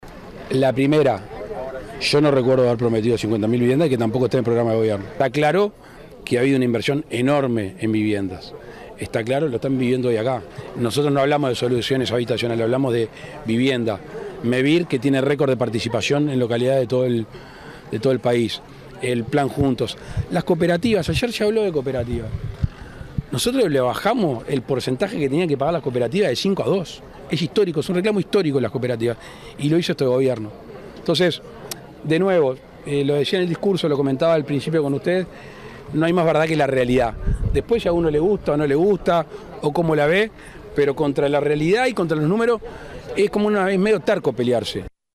El presidente de la República, Luis Lacalle Pou en el marco de la inauguración de viviendas del Plan Avanzar en el departamento de Cerro Largo dijo en en rueda de prensa: «Yo no recuerdo haber prometido 50.000 viviendas y que tampoco esté en el programa de gobierno».